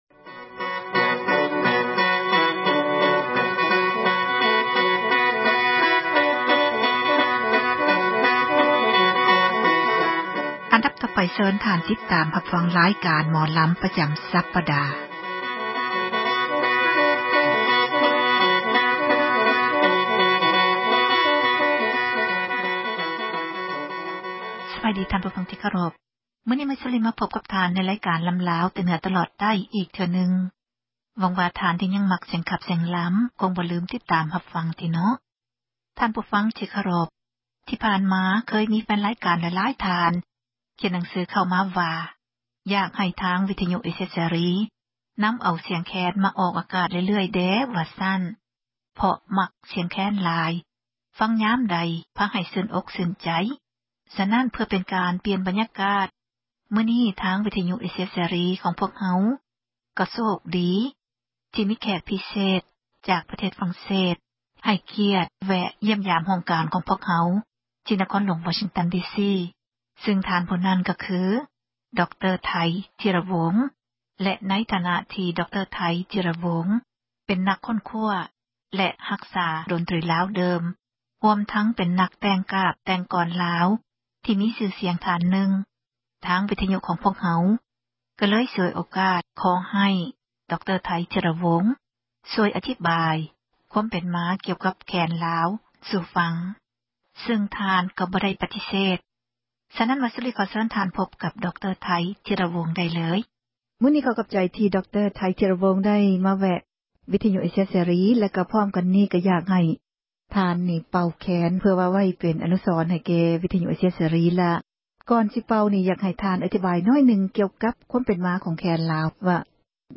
ສິລປະ ການຂັບລໍາ ຂອງ ເຊື້ອສາຍ ລາວ ໃນແຕ່ລະ ຊົນເຜົ່າ ແຕ່ລະ ພື້ນເມືອງ. ເຮົາເອົາມາ ສູ່ກັນຟັງ ເພື່ອ ຄວາມບັນເທີງ ແລະ ຊ່ອຍກັນ ສົ່ງເສີມ ອະນຸຮັກ ໄວ້ໃຫ້ ລູກຫລານ ໃນ ພາຍ ພາກໜ້າ.